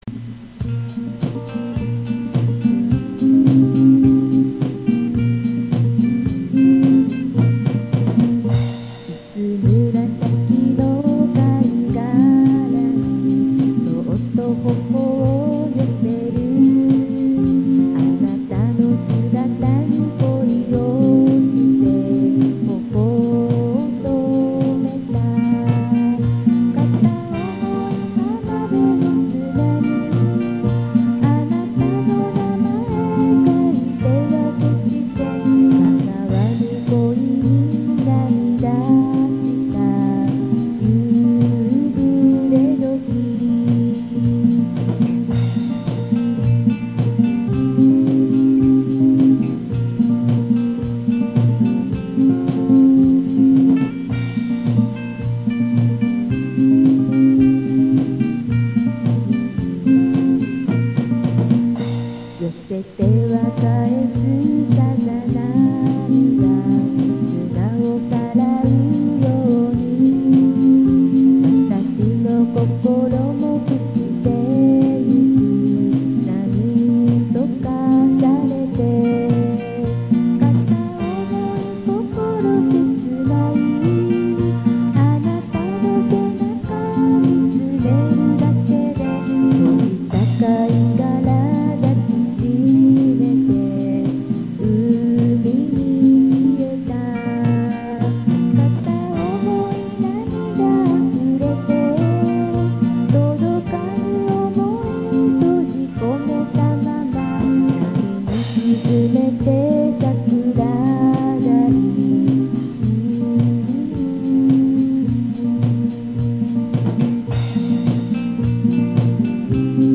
１の音にバックバンドの音を入れました。
録音してみたら昔のフォーク風で、失恋の詩なのに明るい曲になりました。
早速バックの音を入れて録音したら、ダビングを繰り返すので音が劣化して、
低音が強くなりました。